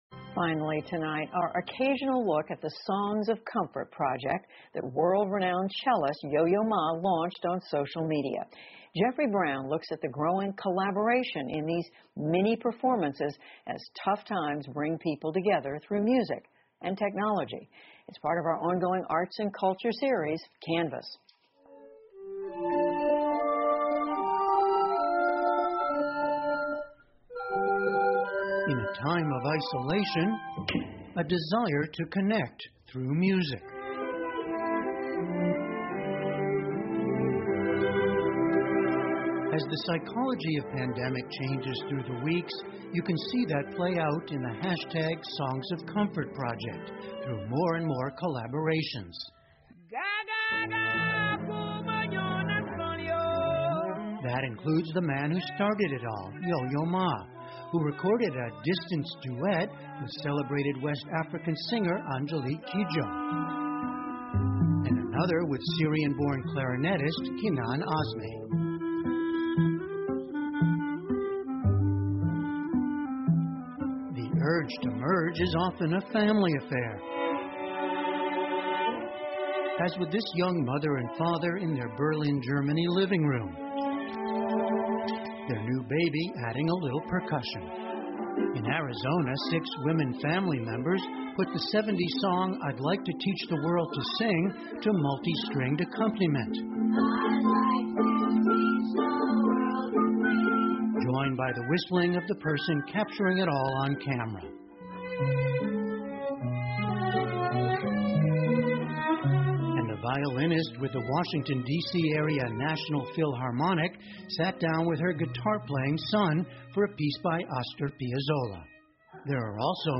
PBS高端访谈:疫情期间的舒压之歌 听力文件下载—在线英语听力室